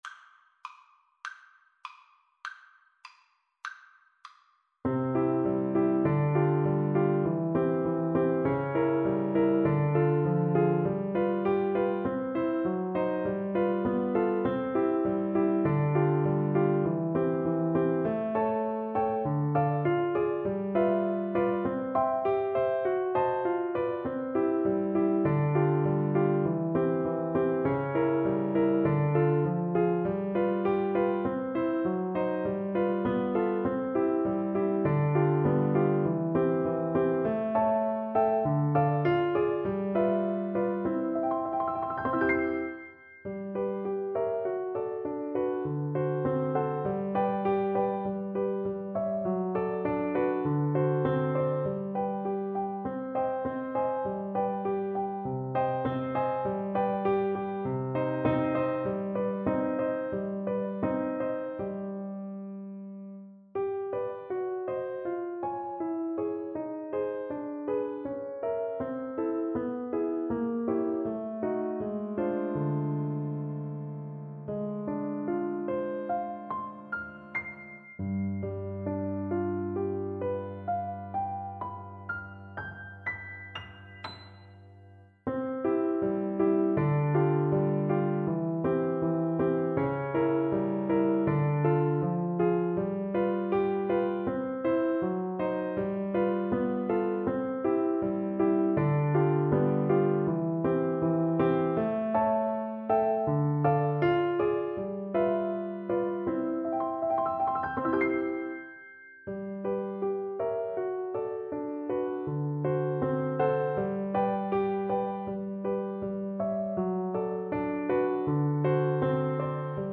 Flute
Moderato assai. = 100 - 116 = 100